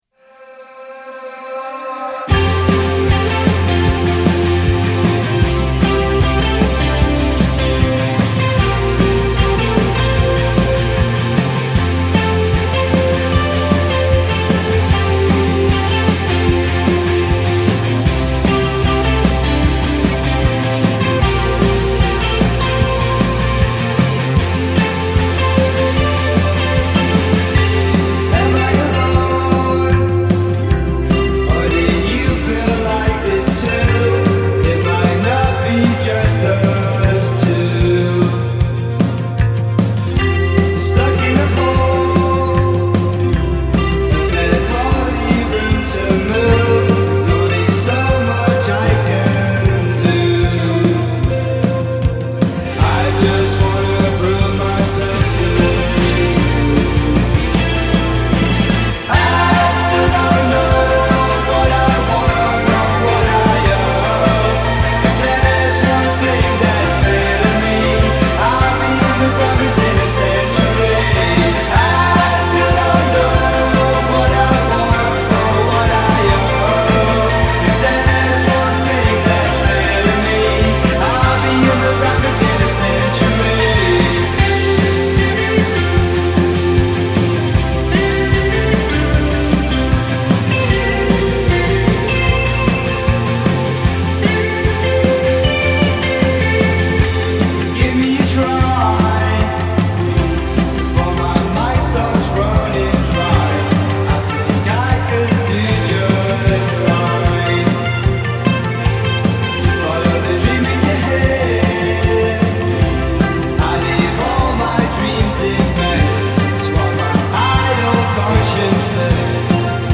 indie
Specialising in summer jams of the highest calibre
another wonderful slice of dream pop